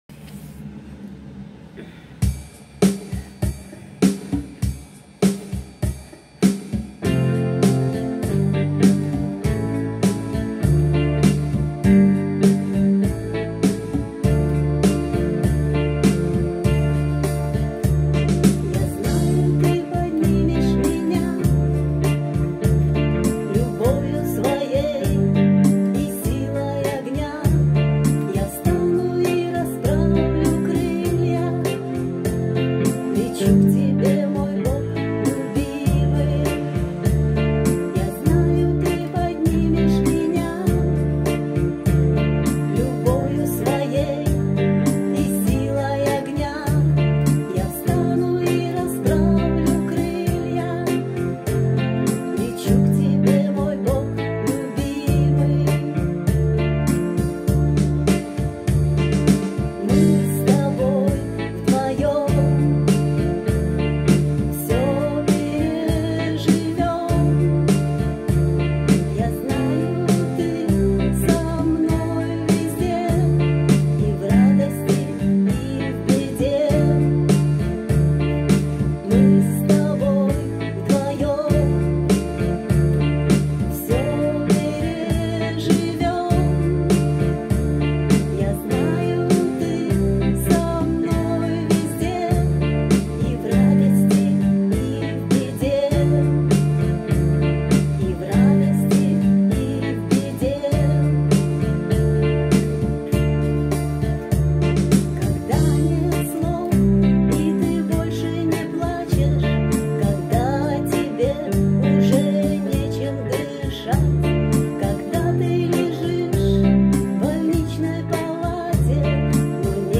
Записала дома уже.